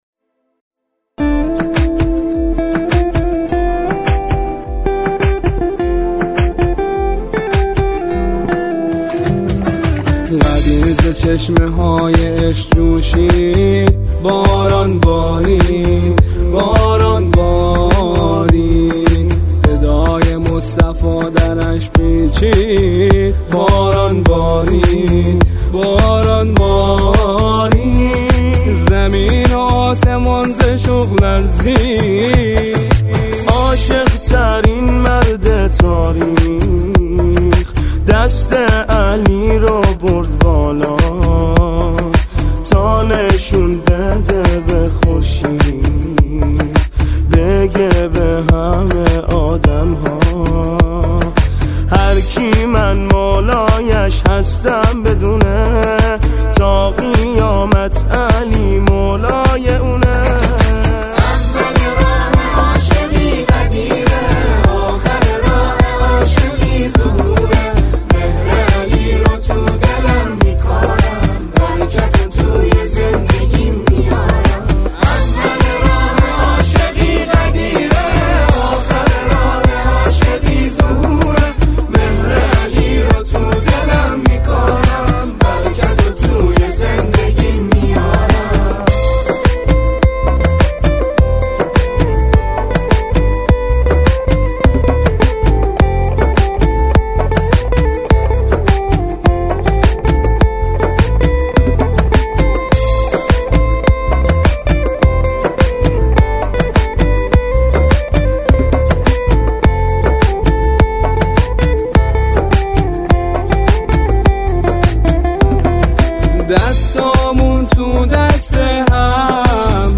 سرودهای اعیاد اسلامی